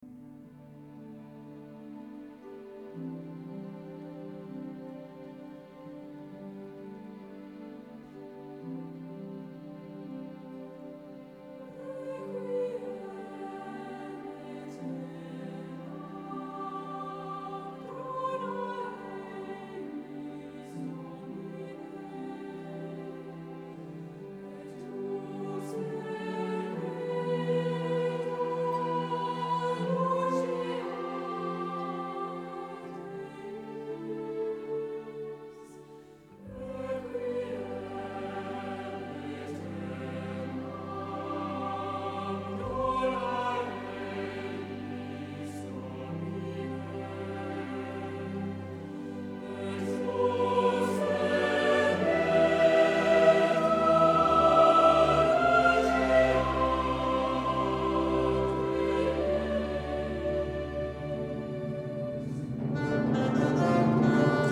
Dans l’une des plus belles églises de Sherbrooke, l’église St-Jean-Baptiste, 80 choristes et 50 musiciens partageront la scène afin d’offrir un spectacle unique.
soprano